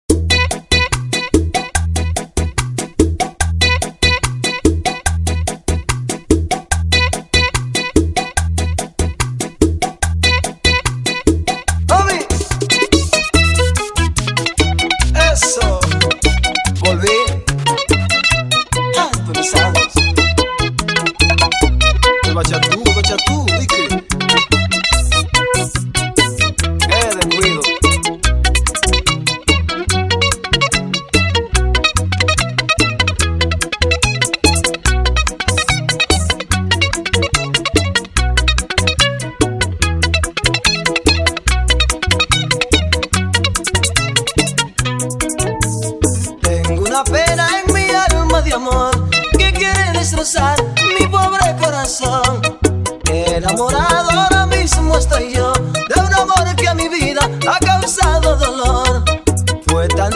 Bachata